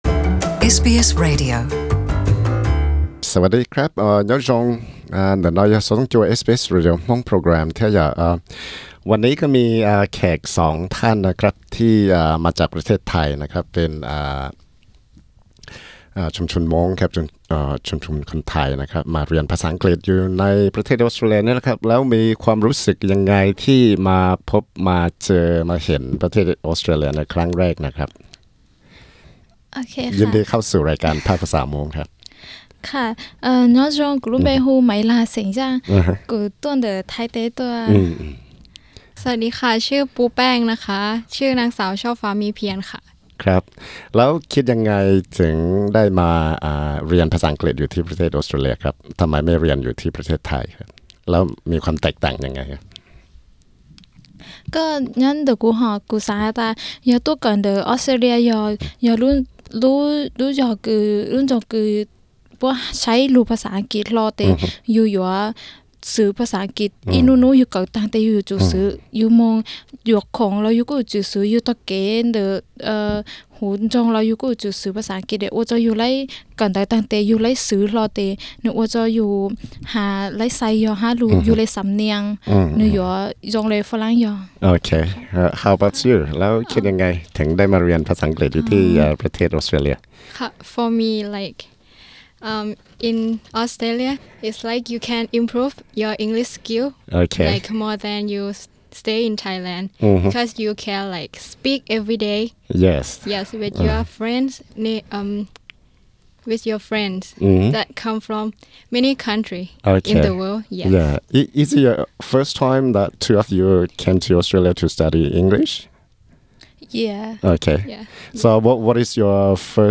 There are many reasons why international students want to study in Australia specifically Melbourne, but will they fulfill their expectations? We have two students from Thailand, one Hmong girl and one Thai girl sharing their insights into why they want to study English in Melbourne?